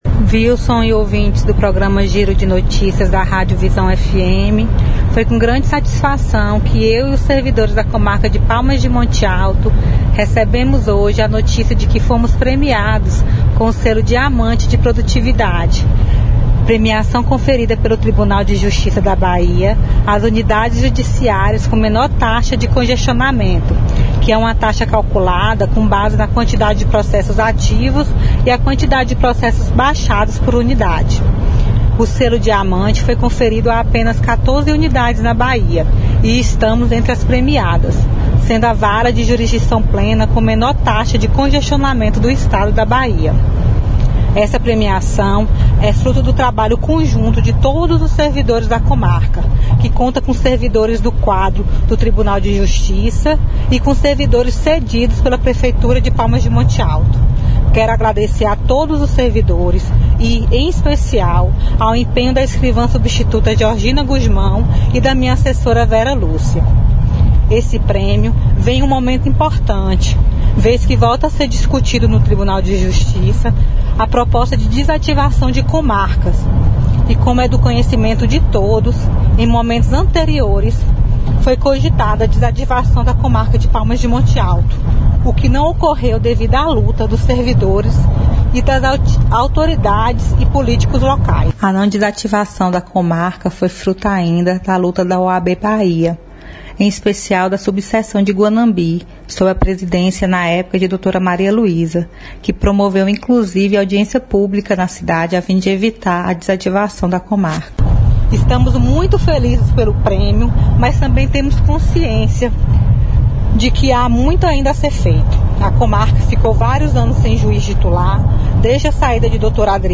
Em entrevista à nossa reportagem, a magistrada destacou que, este prêmio representa o reconhecimento de todo o esforço realizado por todos os funcionários para melhoria da prestação jurisdicional da comarca local. Ela ainda enfatizou que, a honraria concedida ocorreu em um momento fundamental, vez que volta a ser discutida a proposta de desativação de comarcas.